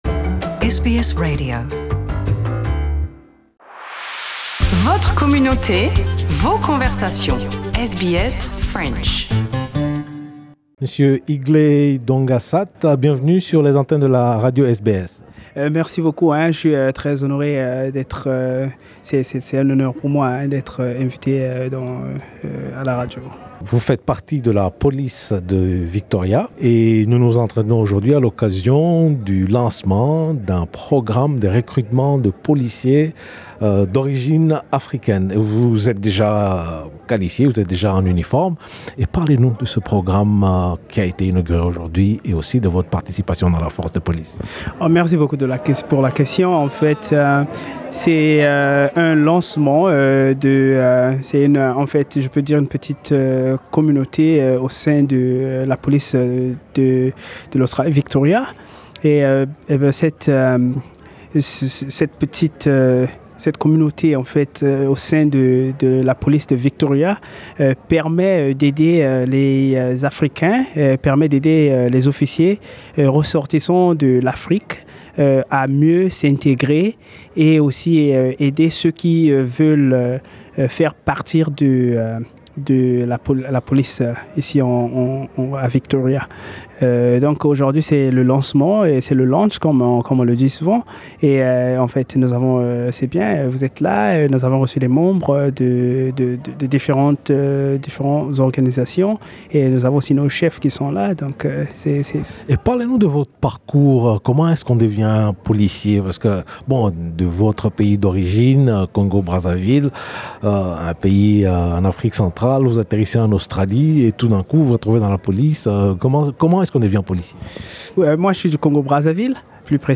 Notre entretien s’est déroulé en marge du lancement d’un réseau de soutien à l’insertion des fonctionnaires de la police du Victoria d’origine Africaine (VICPOLEAN)- Victoria Police African Employee Network. VICPOLEAN a aussi pour ambition de soutenir et guider les australiens d’origine africaine désirant faire carrière dans la police du Victoria et les autres filières associées.